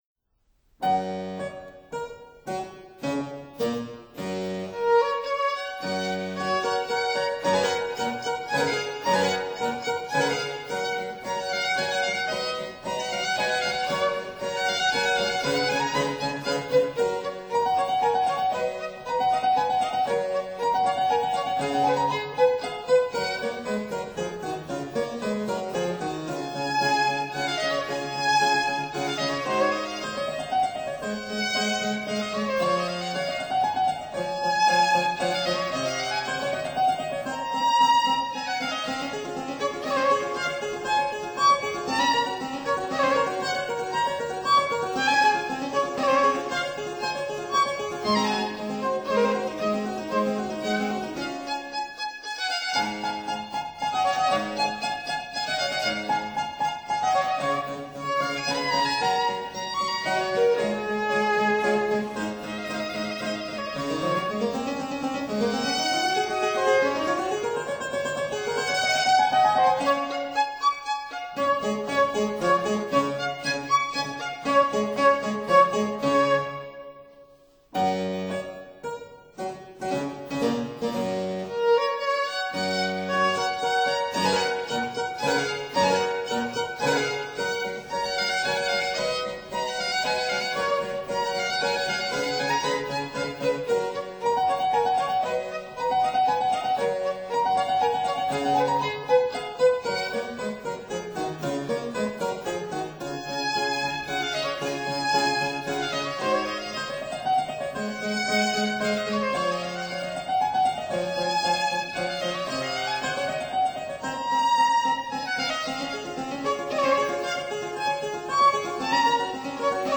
Sonata in A major for Piano, Violin & Cello, B47a (1761)
Tangent Piano
Violin
(Period Instruments)